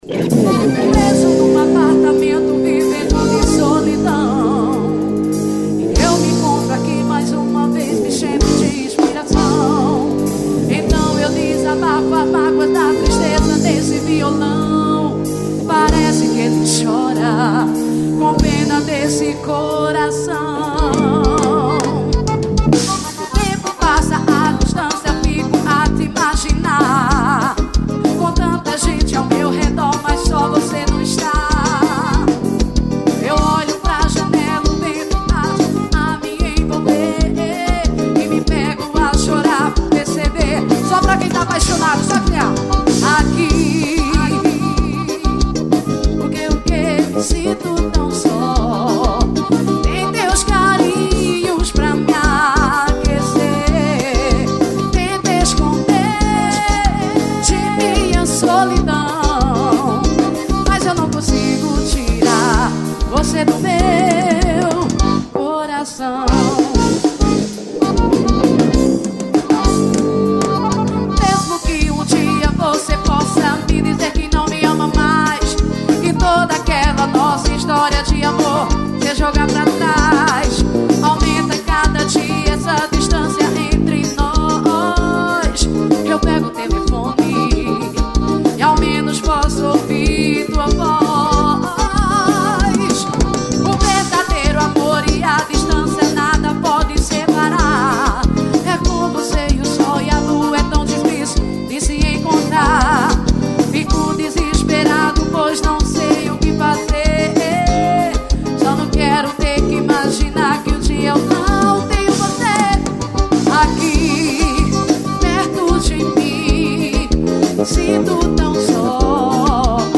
Show ao VIVO.